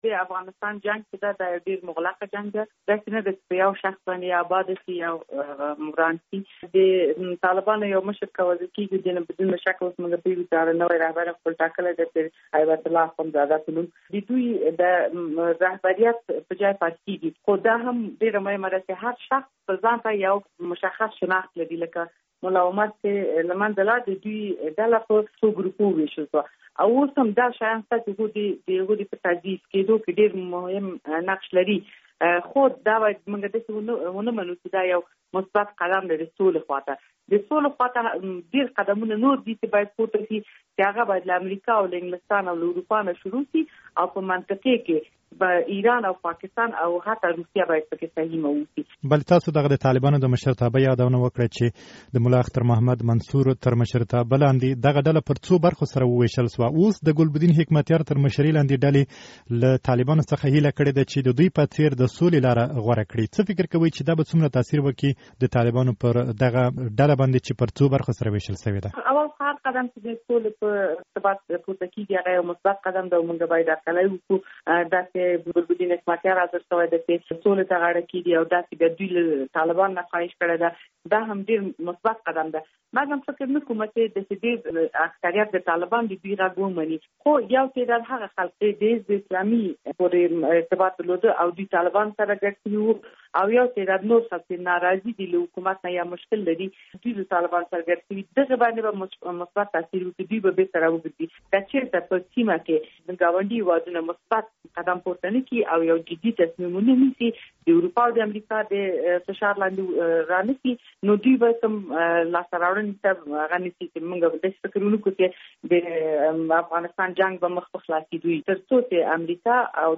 د ولسي جرګې له استازې هيلۍ ارشاد سره مرکه